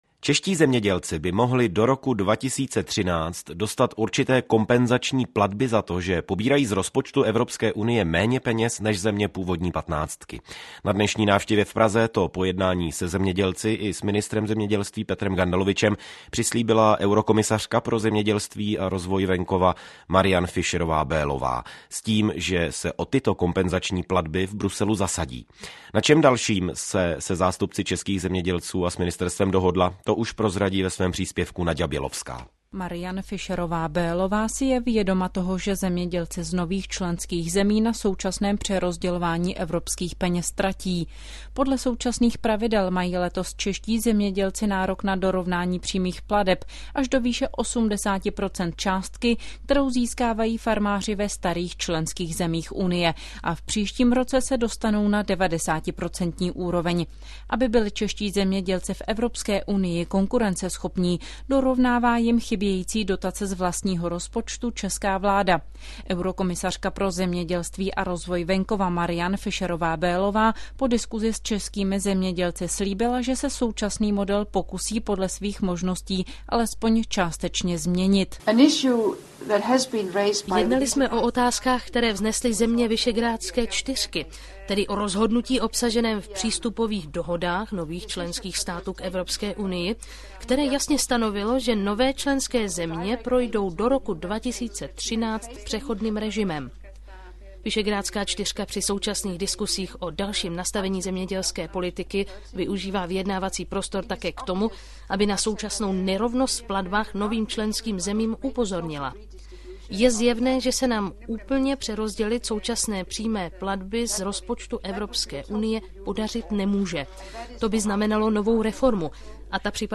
Rozhovor na aktuální téma